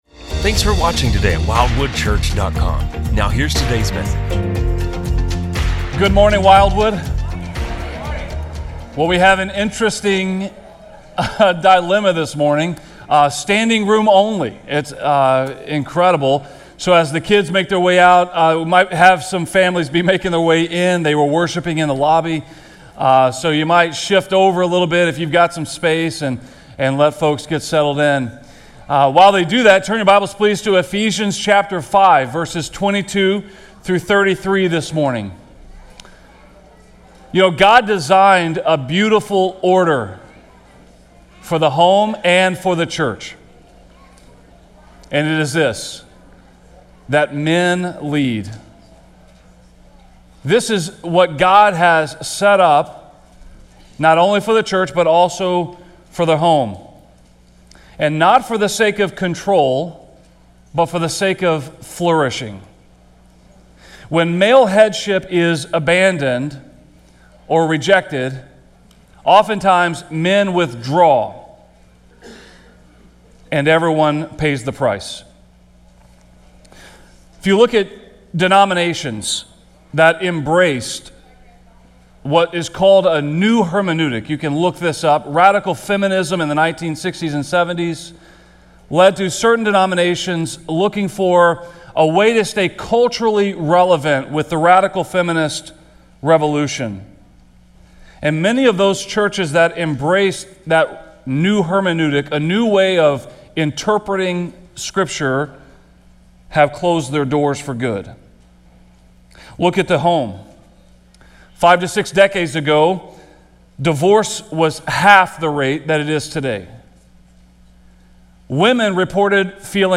In this sermon, we explore Ephesians 5:22–33 to recover the beauty of biblical headship and the powerful impact of faithful, godly leadership in the home.